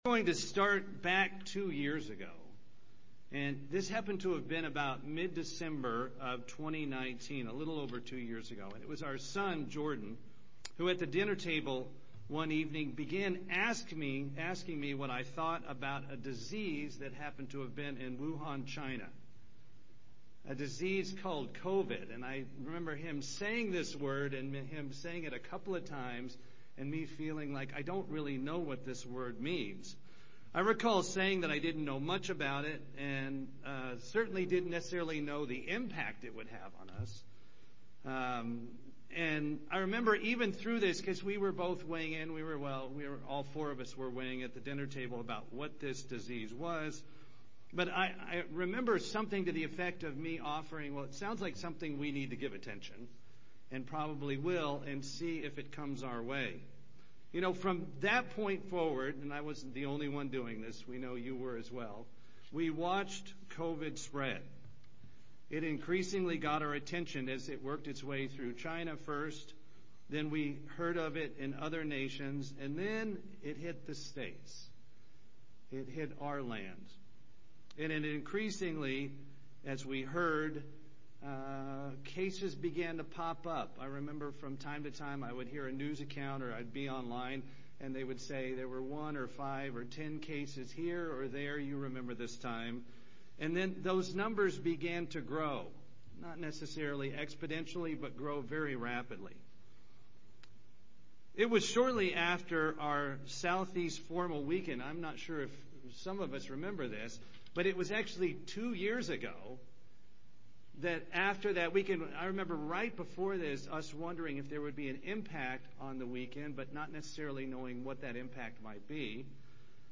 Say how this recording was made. Given in Atlanta, GA Buford, GA